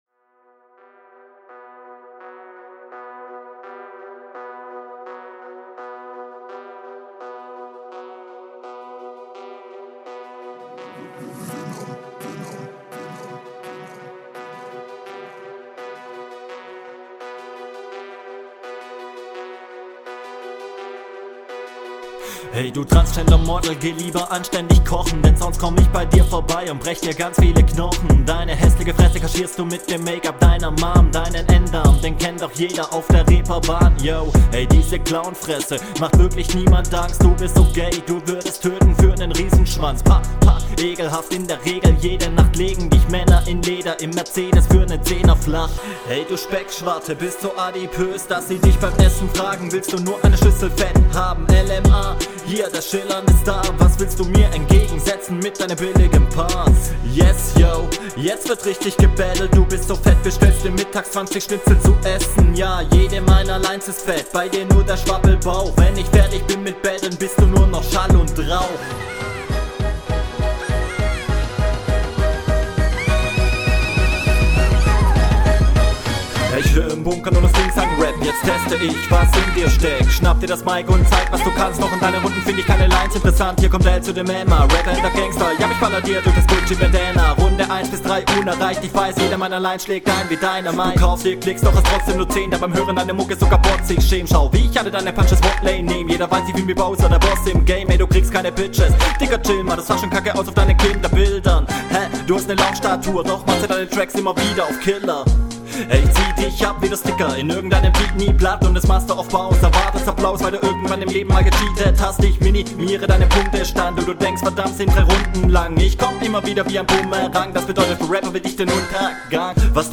Flow: Flow ist ganz gut. Doubletime ist auch nice. Takt on Beat.